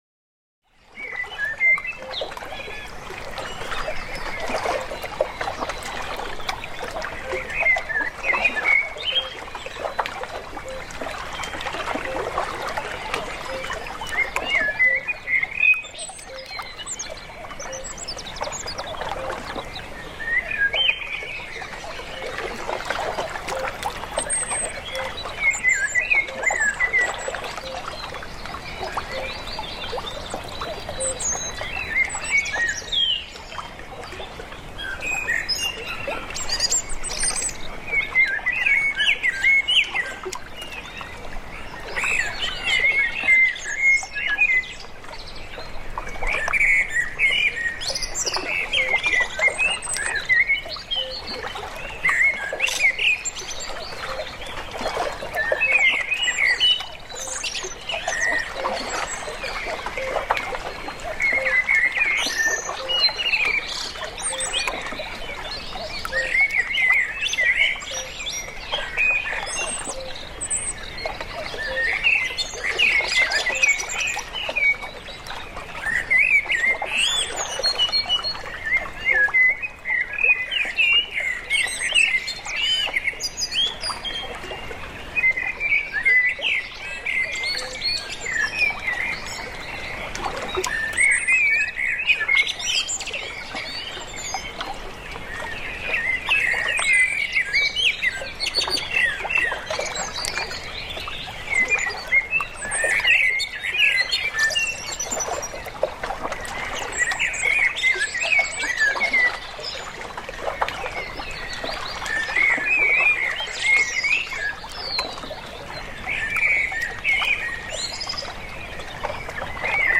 WASSER-FRIEDEN-TRÄGER: Seeufer-Waldwind trägt Stille über Wasser